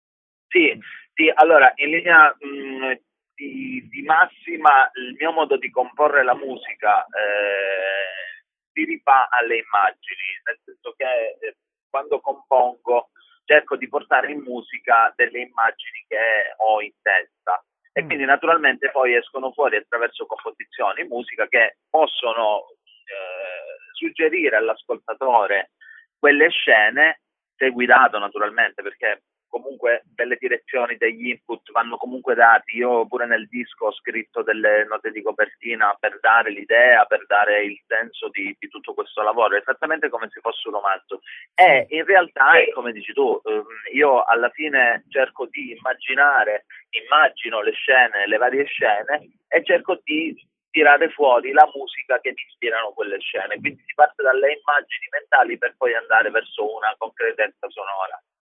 La telefonata